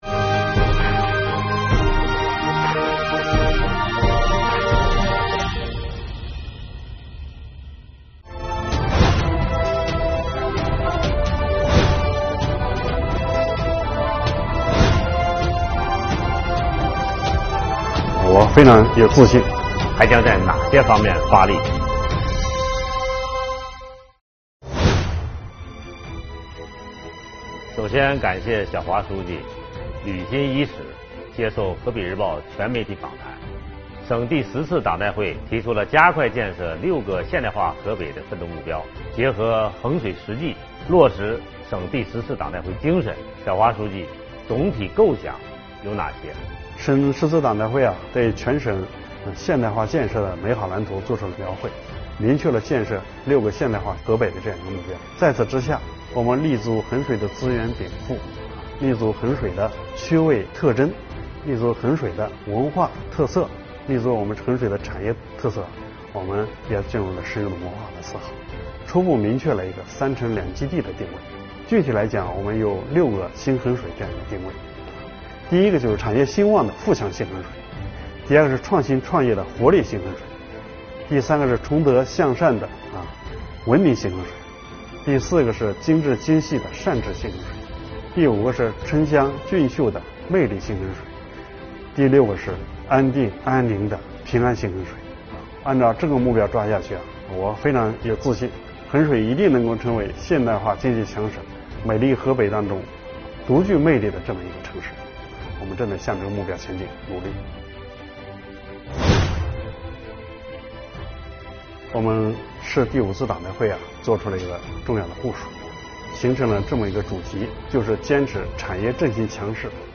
河北日报专访衡水市委书记吴晓华：打造诗意栖居的幸福之城